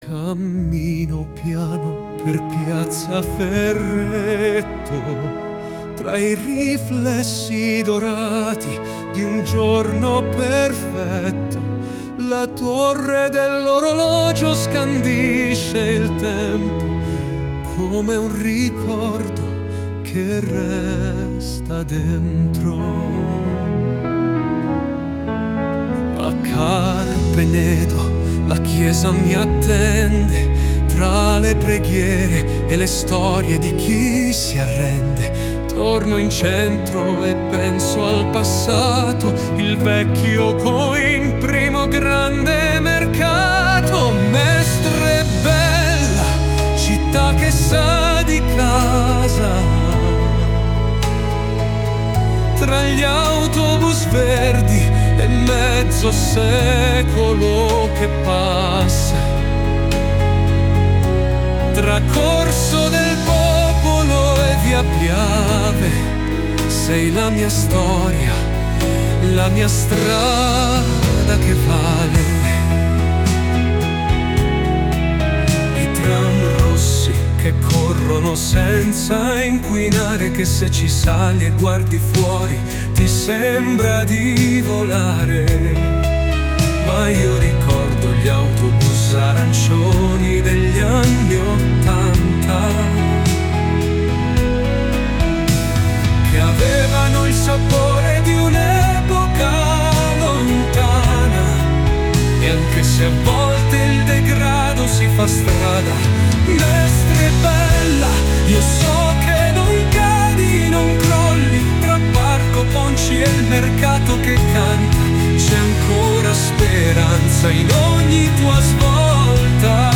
La magia dell'intelligenza artificiale applicata alla musica fa sì che basti poco più di un "clic" (e un po' di fantasia) per riarrangiare completamente un brano.
Per chi non ama la musica particolarmente moderna e preferisce uno stile più classico, ho elaborato questa versione sempre con Suno.
Mestre_bella-versione_alternativa.mp3